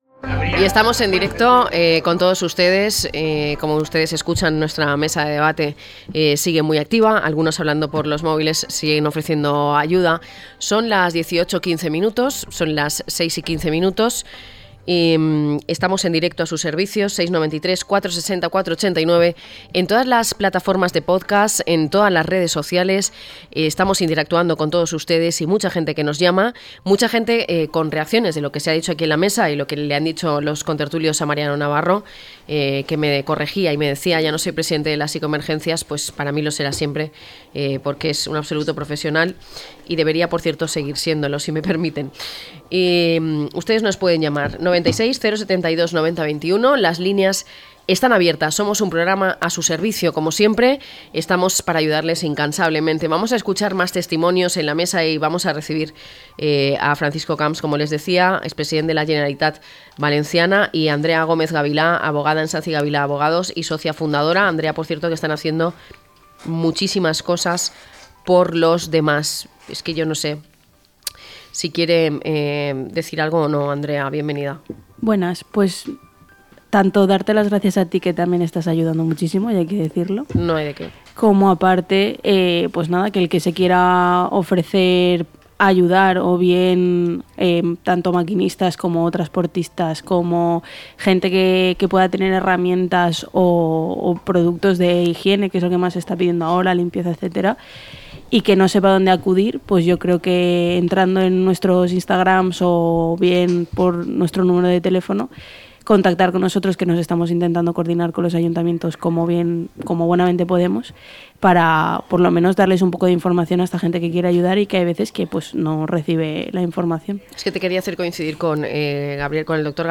Hablamos con expertos y recibimos testimonios de afectados por la DANA de Valencia.